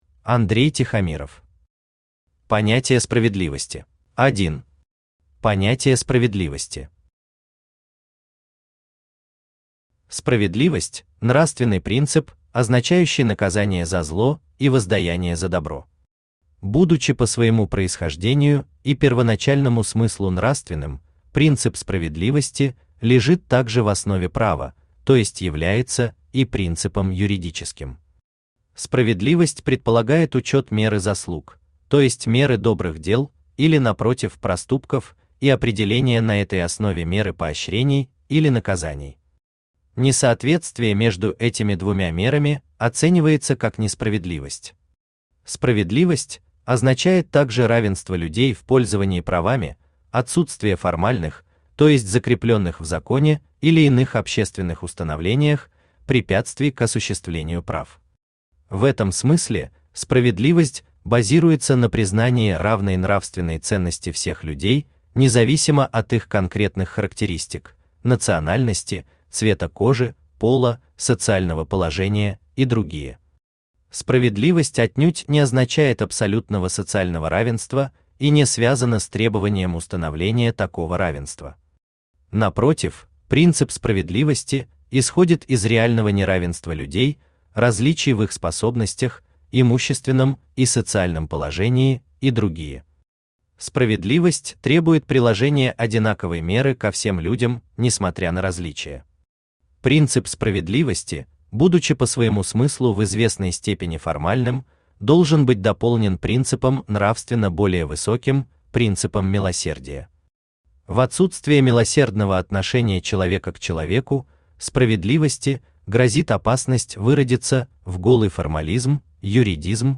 Аудиокнига Понятие справедливости | Библиотека аудиокниг
Aудиокнига Понятие справедливости Автор Андрей Тихомиров Читает аудиокнигу Авточтец ЛитРес.